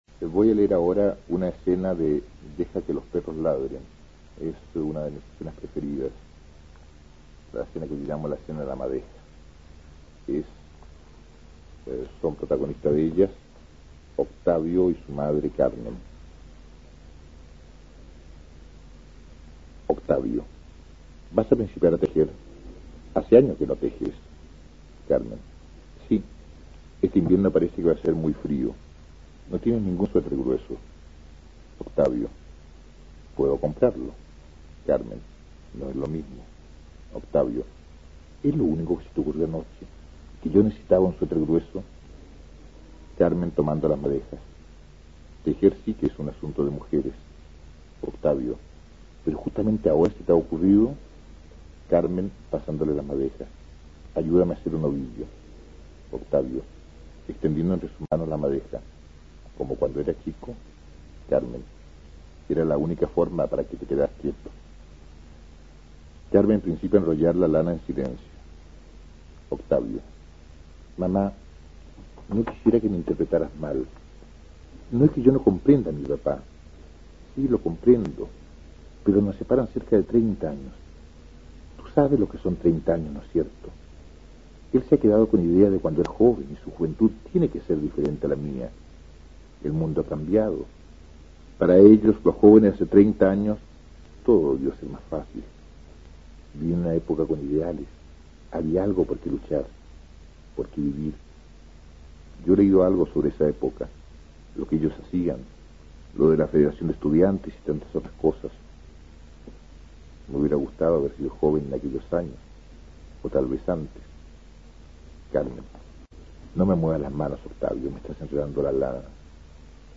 Aquí podrás escuchar al dramaturgo chileno Sergio Vodanovic leyendo la llamada escena de la madeja, de su obra "Deja que los perros ladren" (1959), con la cual obtuvo el Premio Municipal. Se trata de un drama social que explora críticamente en la condición de la clase media chilena.